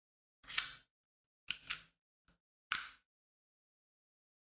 手枪子弹上膛
描述：重装子弹上膛音效，干净的单音
标签： 手枪 武器 重装
声道立体声